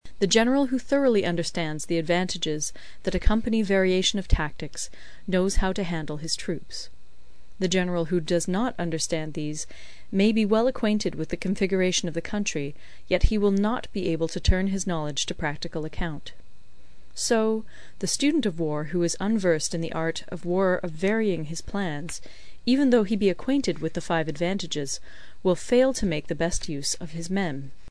有声读物《孙子兵法》第46期:第八章 九变(2) 听力文件下载—在线英语听力室